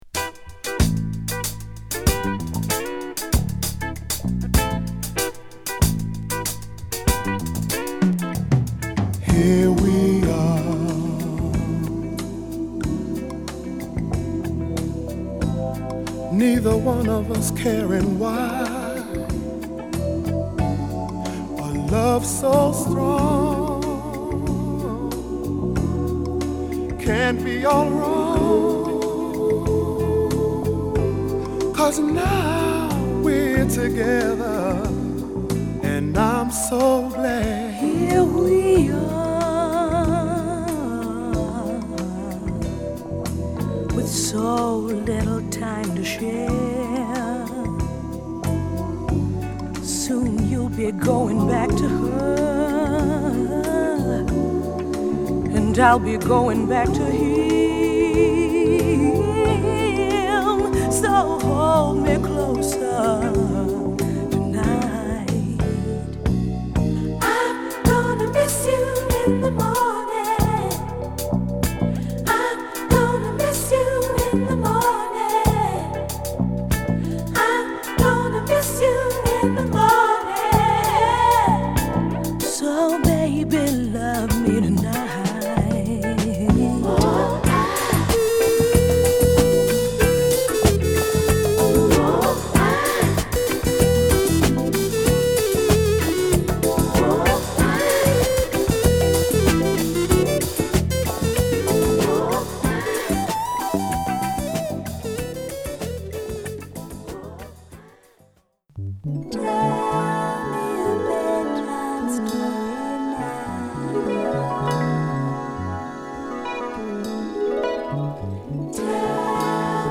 メロウ・フュージョン〜熱いディスコまでを収録！